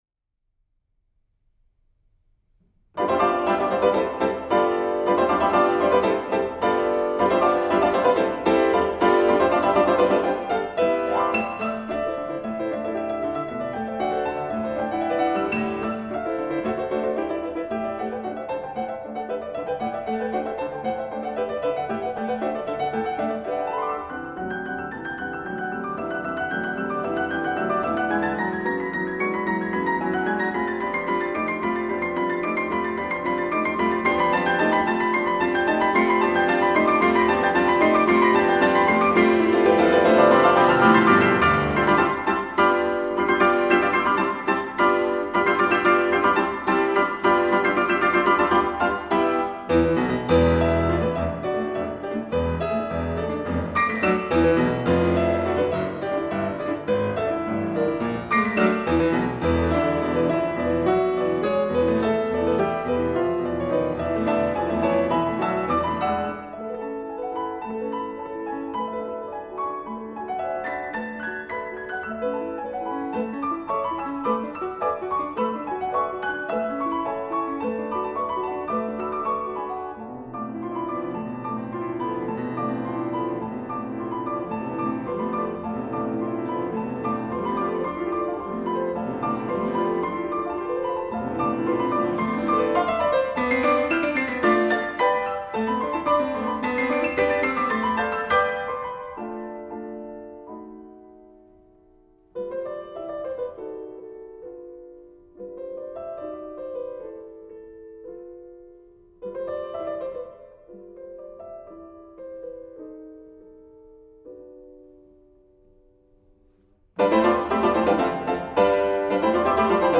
LIVE.